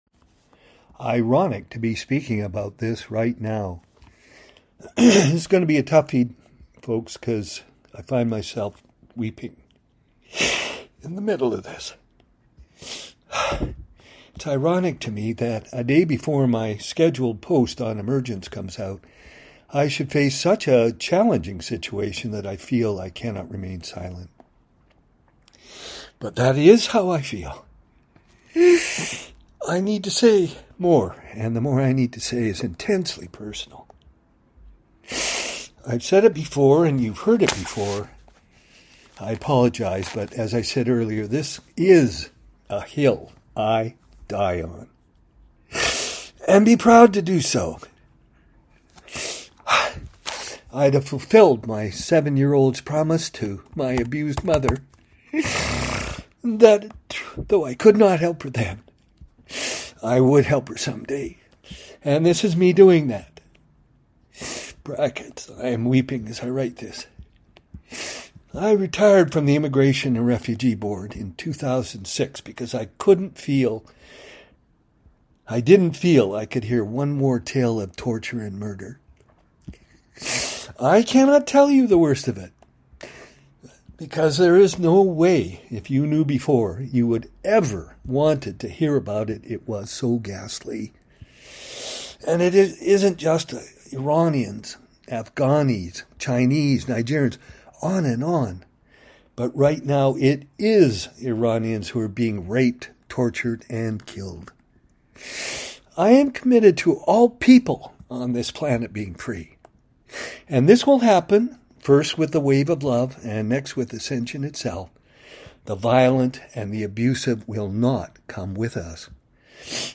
Please do not read this if you do not want to hear a grown man weep and talk about conditions that no one should have to go through